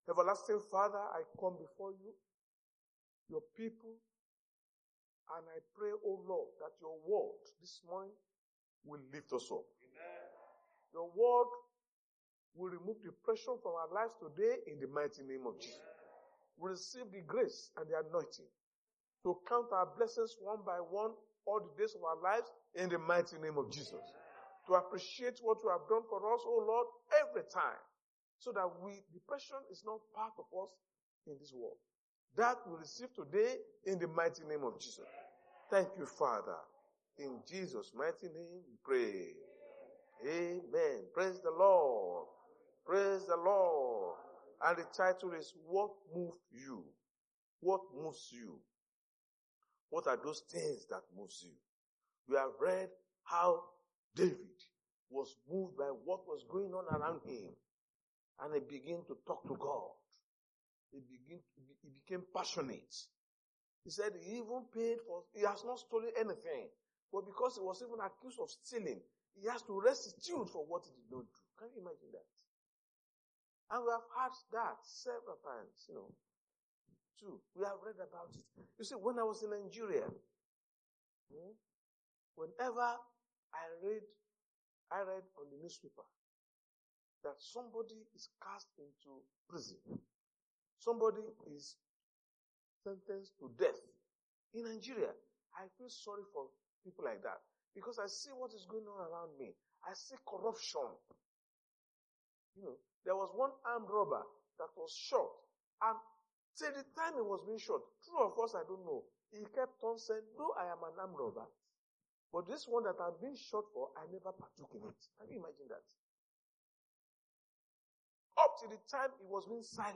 Service Type: Sunday Church Service